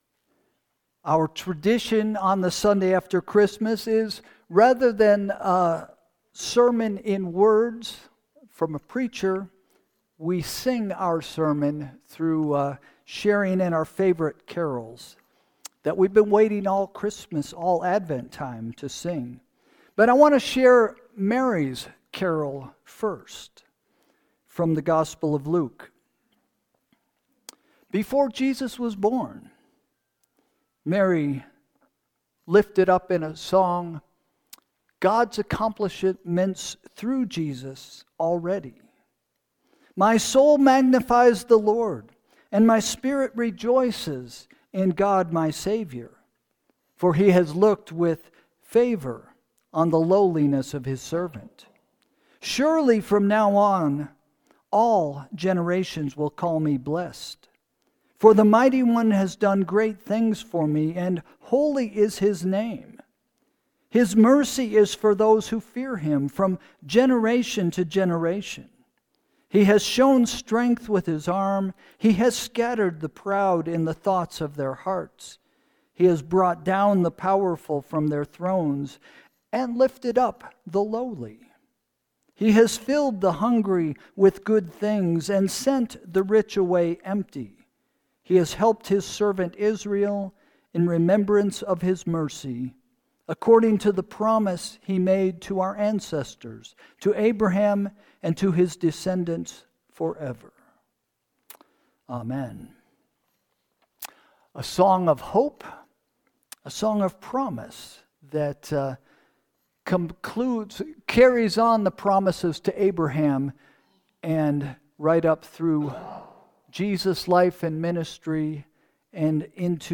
This week, instead of our regular sermon we have a special time for the Christmas Carols of the season. Join us as we sing our favorites!